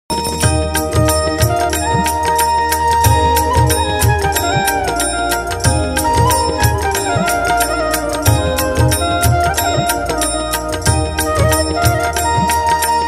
peaceful and divine flute tunes